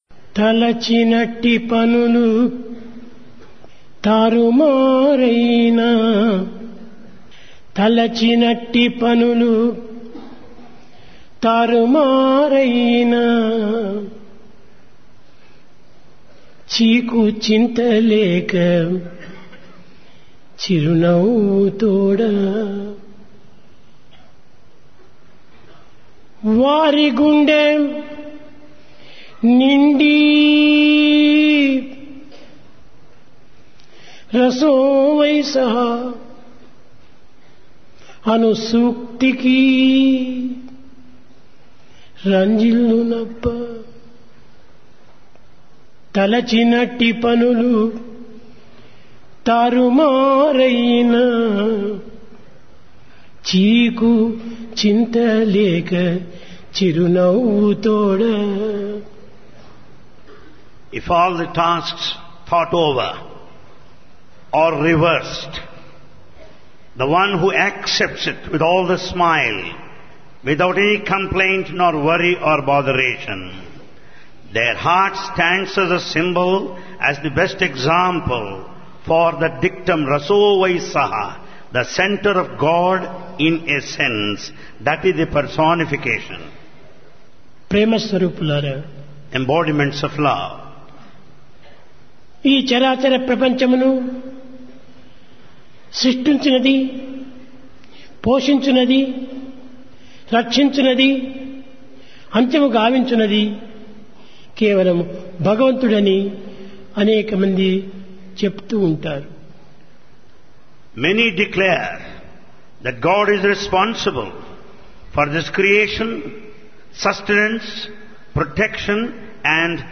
Divine Discourse of Bhagawan Sri Sathya Sai Baba
Place Prasanthi Nilayam Occasion Dasara